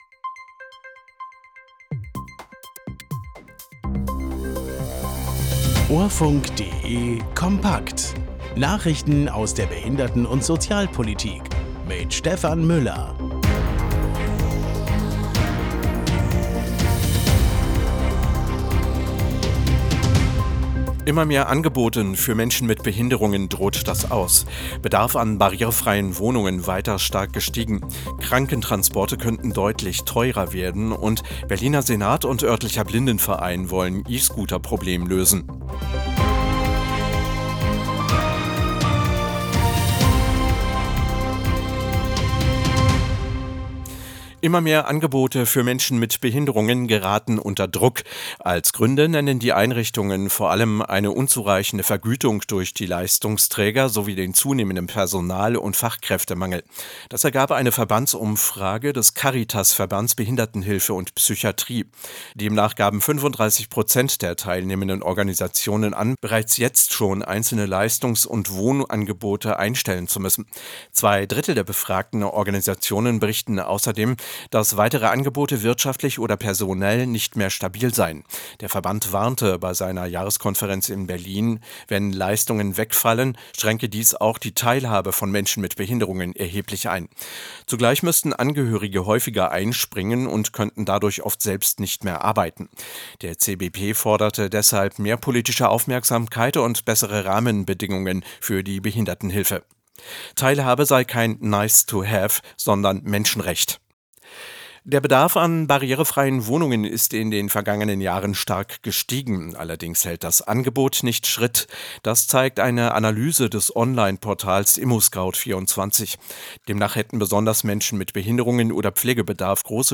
Nachrichten aus der Behinderten- und Sozialpolitik vom 18.03.2026